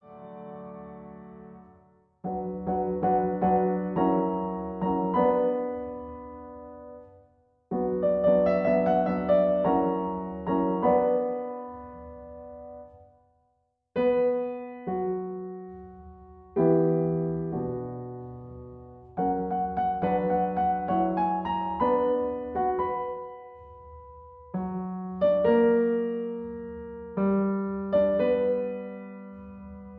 Piano accompaniment in B minor